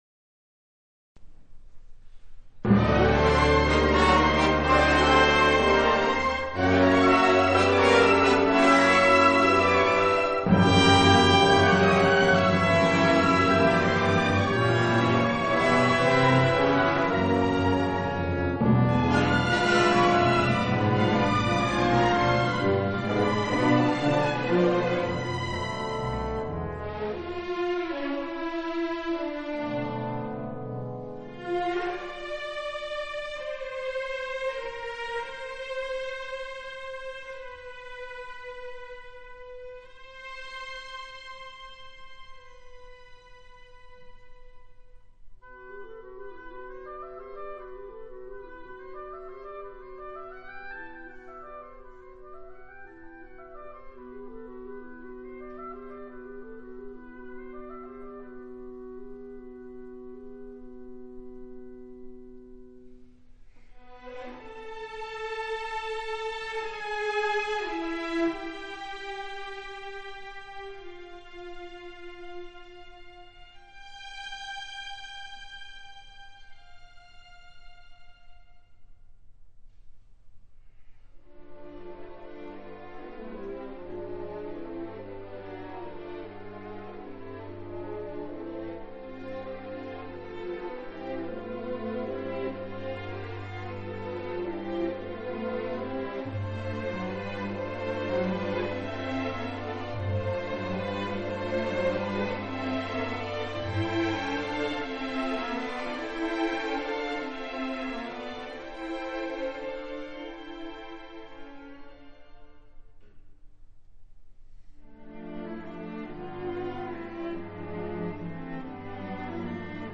(指揮)ファンホ・メナ
(管弦楽)ＮＨＫ交響楽団